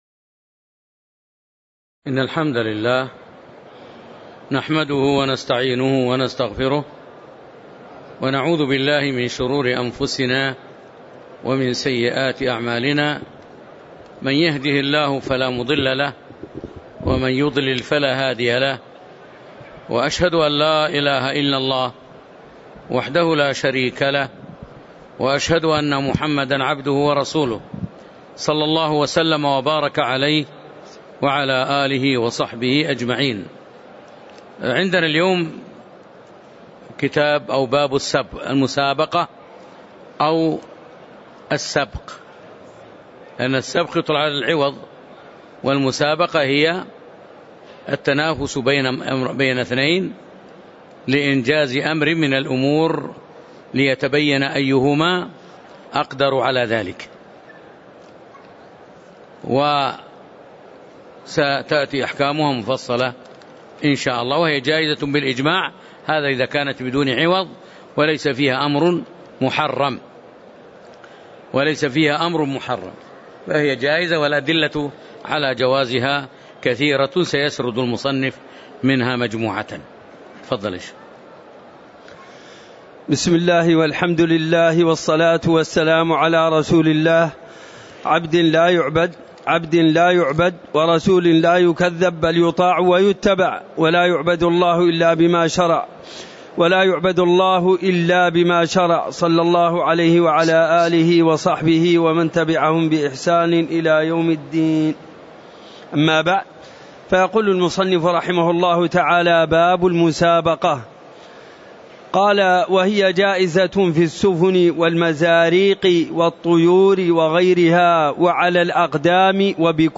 تاريخ النشر ٦ رمضان ١٤٤٣ هـ المكان: المسجد النبوي الشيخ